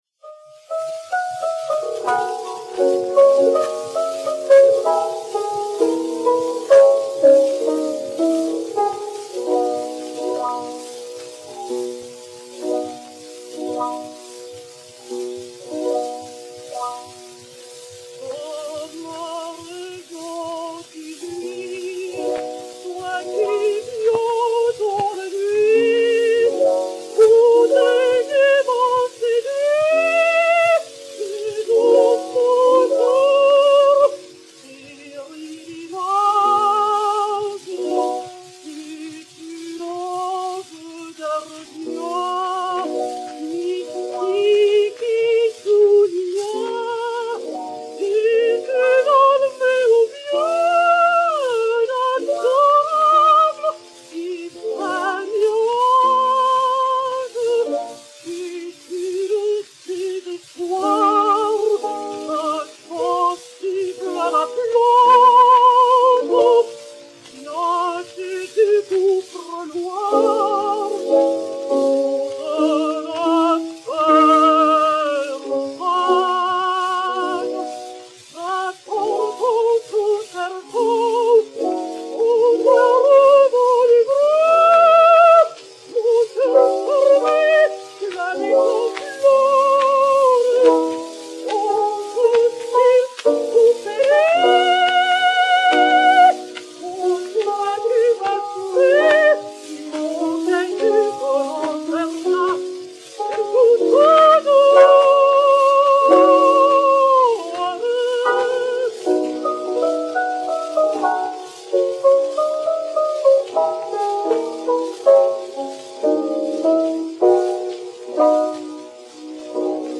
Emma Calvé, soprano, et Piano
Disque Pour Gramophone 3283, enr. à Londres en 1902